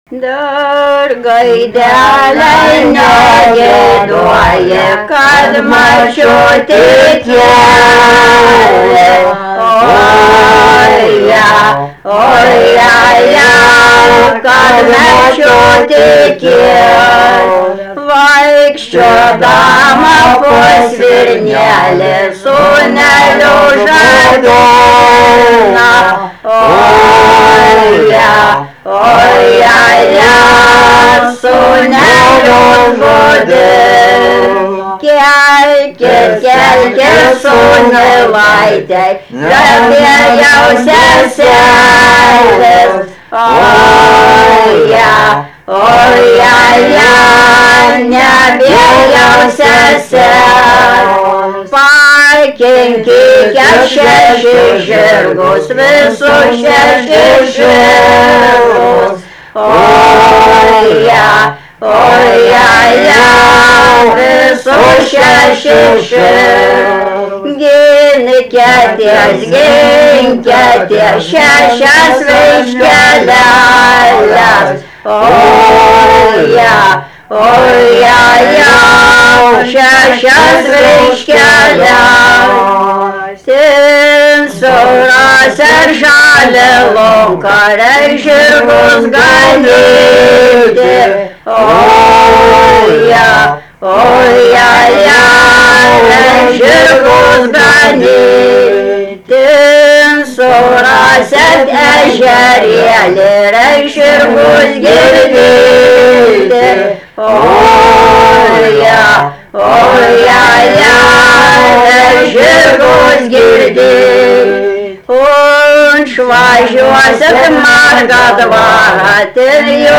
daina, vestuvių
vokalinis
2-3 balsai